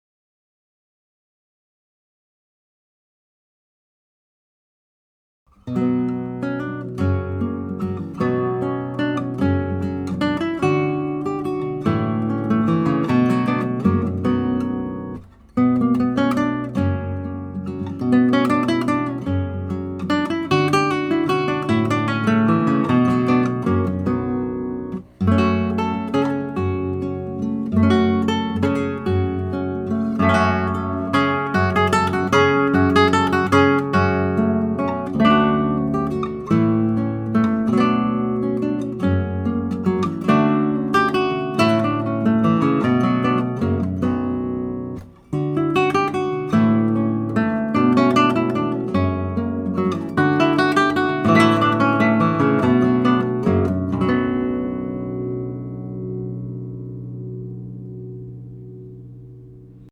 Schoeps Vintage CMT 341 Microphone: CMT 3 body with MK41 hypercardioid capsule
Paulino Bernabe 10-String Classical Harp Guitar | 1 |
SIGNAL CHAIN: Schoeps CMT 341 / Presonus ADL 600 / Rosetta 200 / Logic.